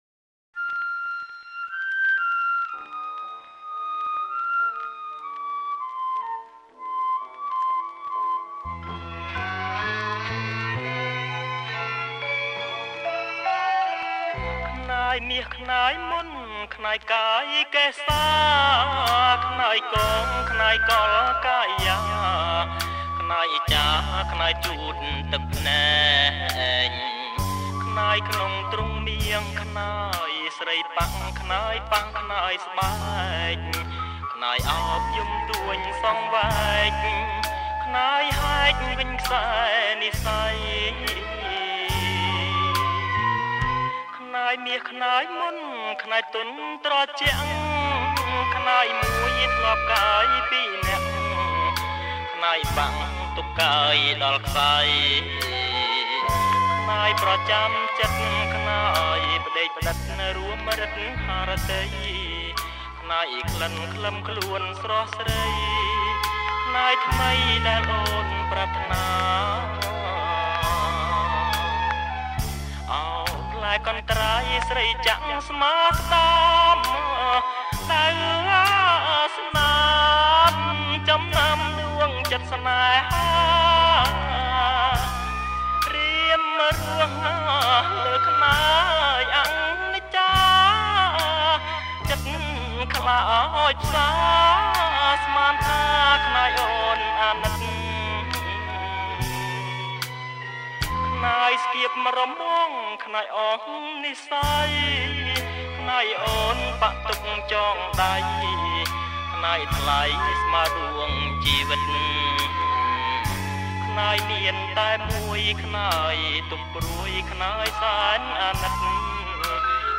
• ប្រគំជាចង្វាក់ Blue